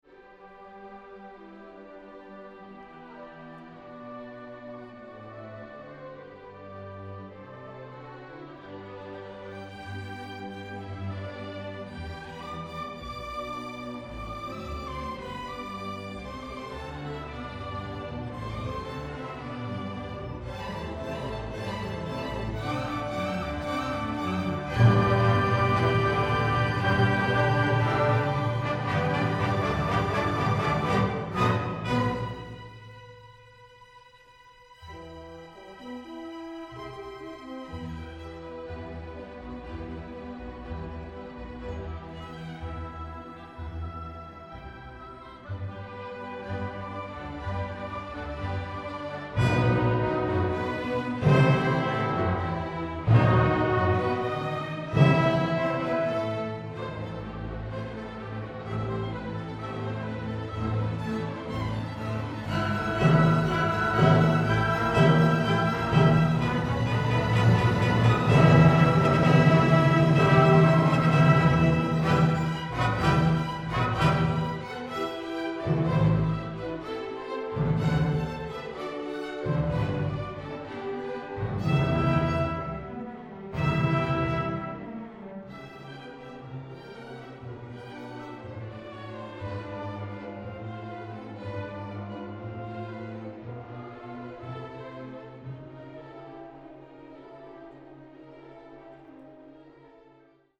(Senior Orchestra)